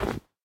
snapshot / assets / minecraft / sounds / step / snow1.ogg
snow1.ogg